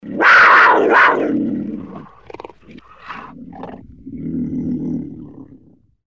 Звуки пантеры
Злобный рык